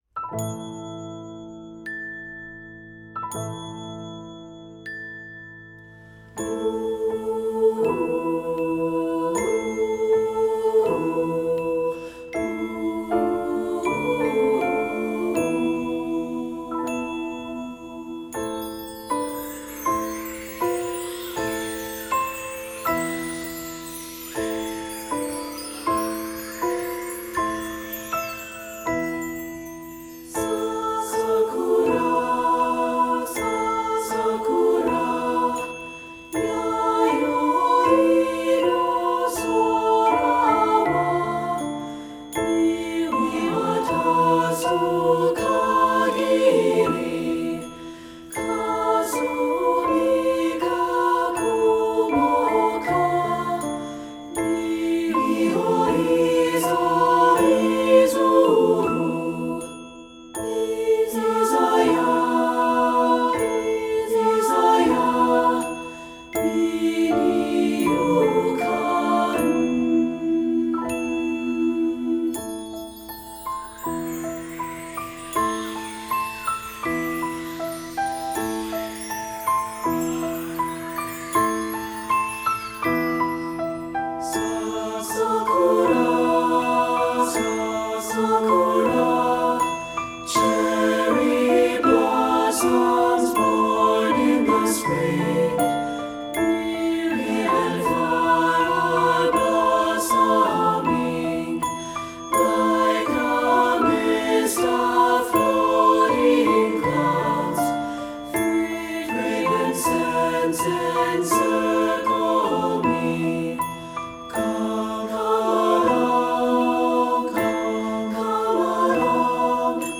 Voicing: 3-Part Mixed